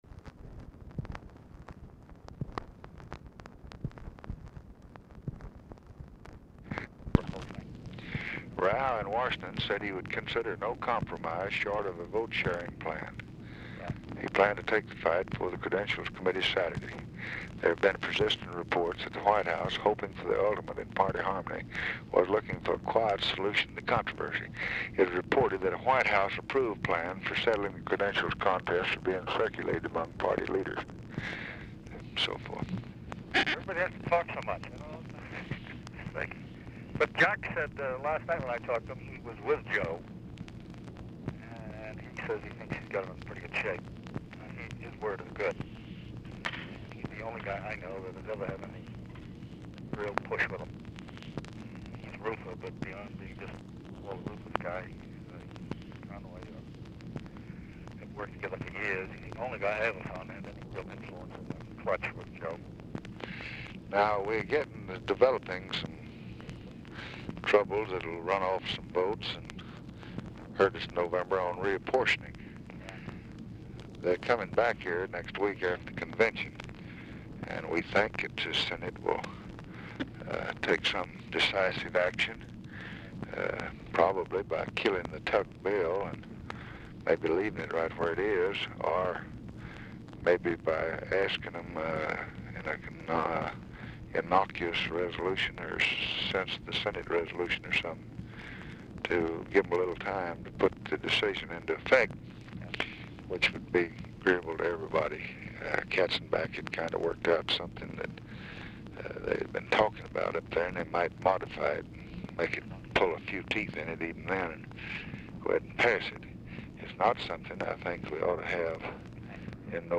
Telephone conversation # 5064, sound recording, LBJ and KEN O'DONNELL, 8/21/1964, 10:46AM | Discover LBJ
Format Dictation belt
Specific Item Type Telephone conversation